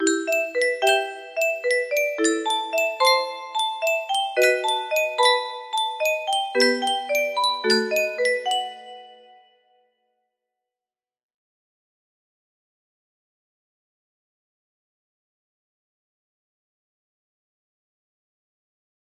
looks good music box melody